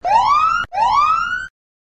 alert-sound.7a0f2090a571b9a80046.mp3